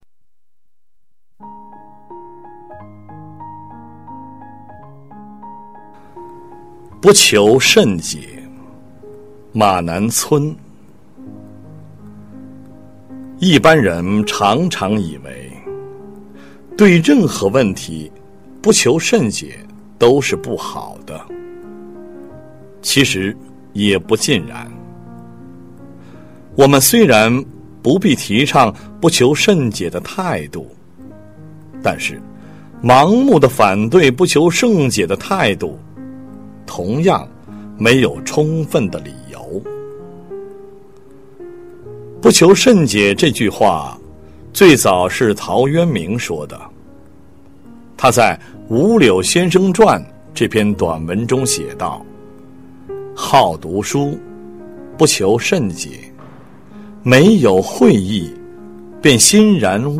九年级语文下册 13《不求甚解》男声配乐朗读（音频素材）